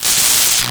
boltexplode3.wav